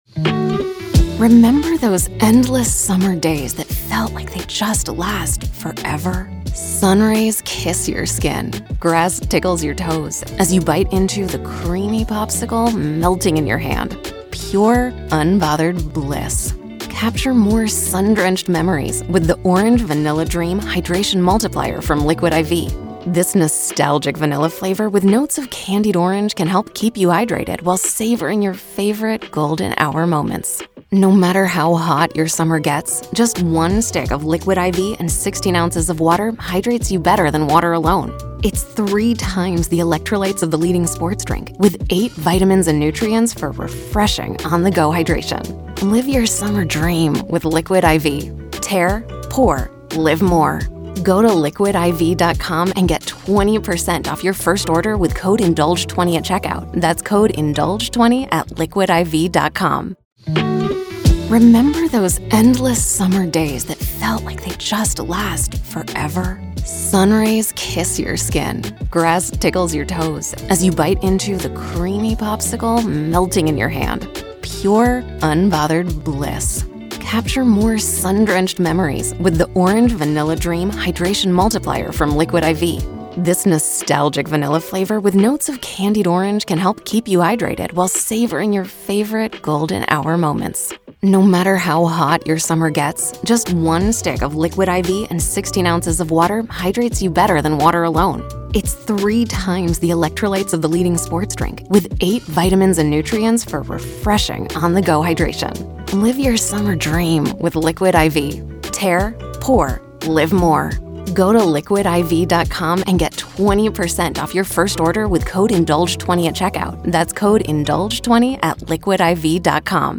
This is audio from the courtroom in the high-profile murder retrial of Karen Read in Dedham, Massachusetts.